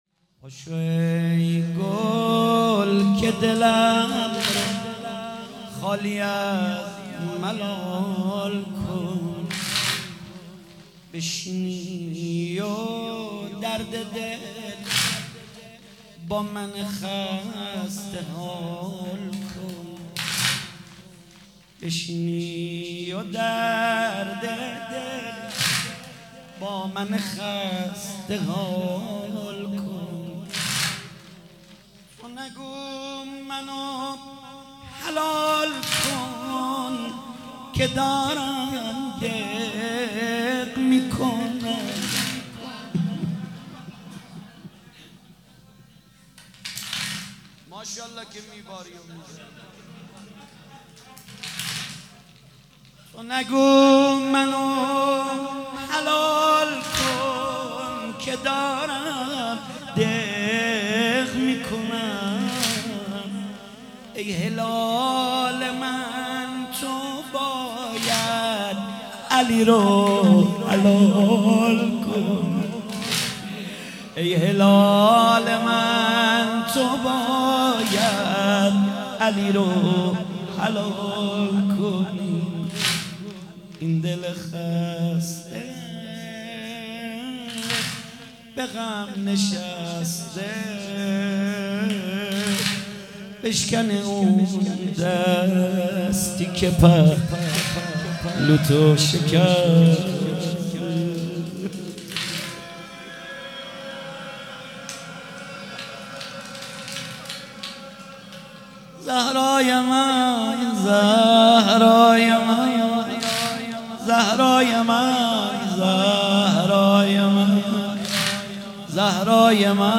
مداحی
ایام فاطمیه 1439 | محفل عزاداران حضرت زهرا (س) شاهرود